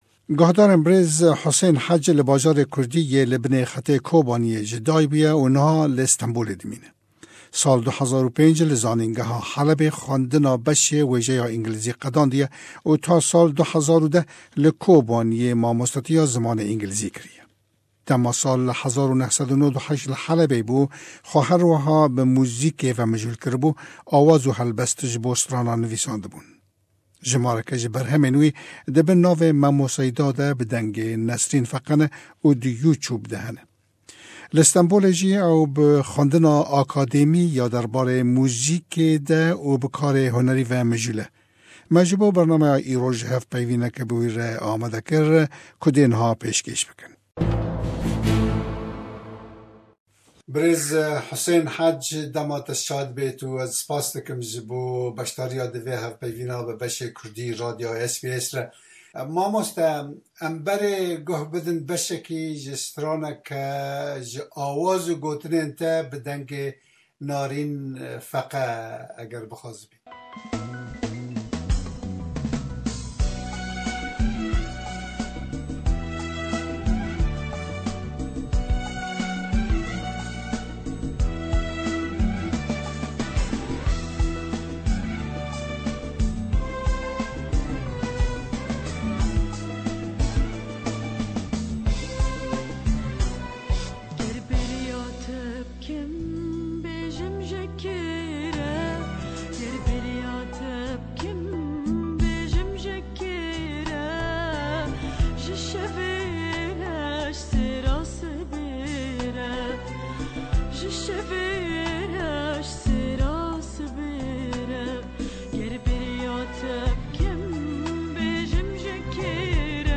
Me derbarê kar û projeyên wî de hevpeyvînek pê re bi rê xist.